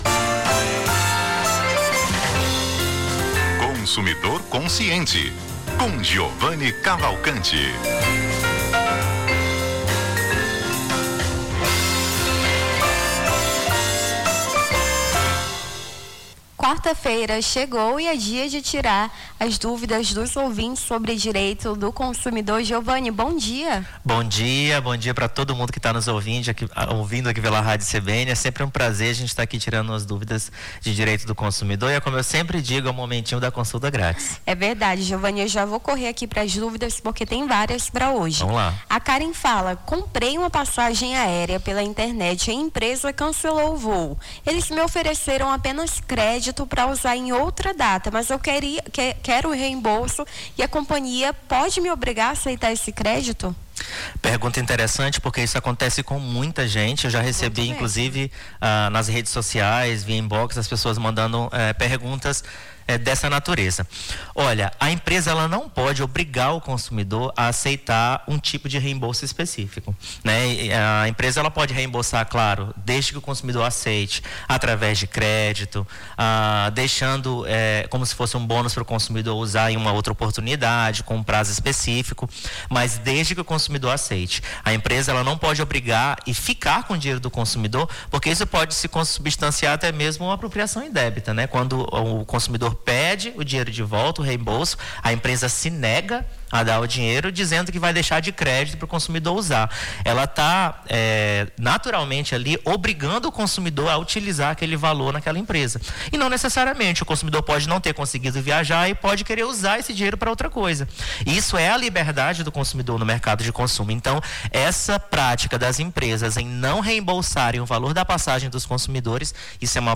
Consumidor Consciente: advogado tira dúvidas sobre direito do consumidor